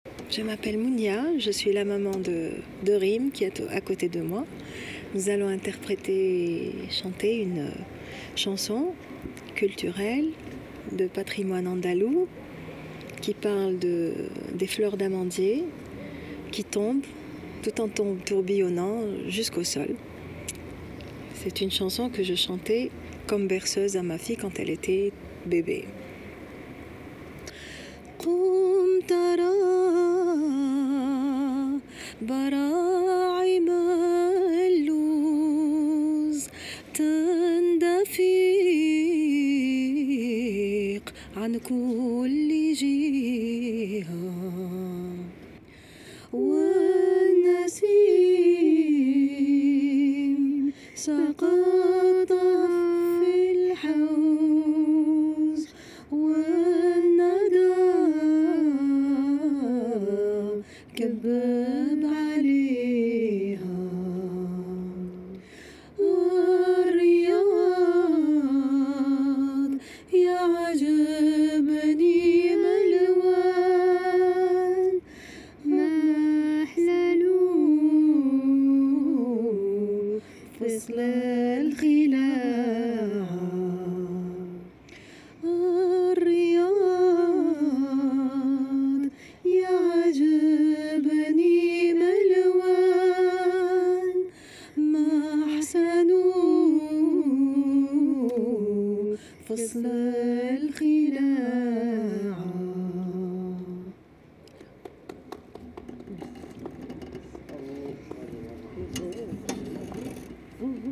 Chants, berceuses, paysages sonores... récoltés dans nos itinérances.